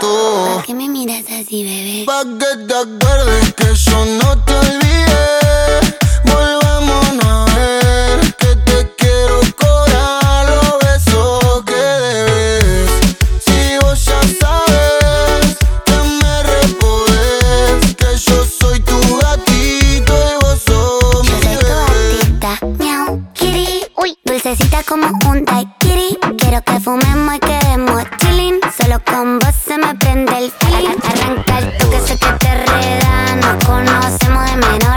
# Latin